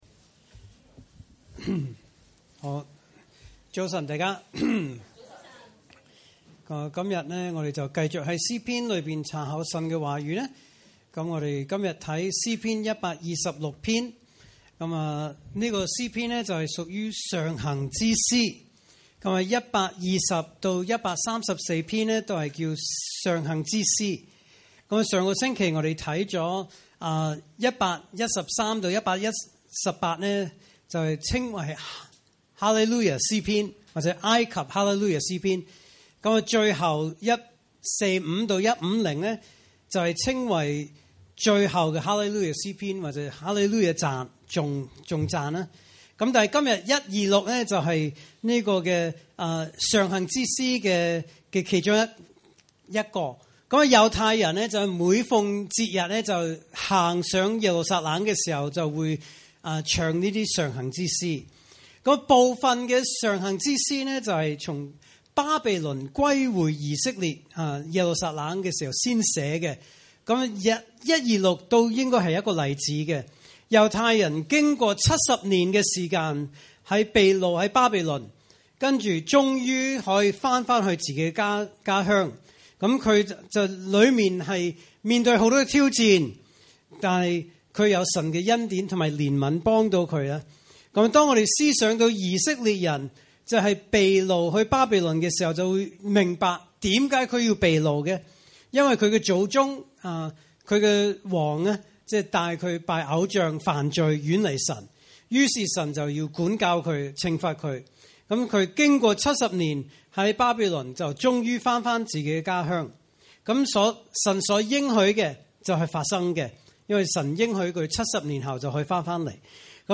主日崇拜證道系列
來自講道系列 "解經式講道"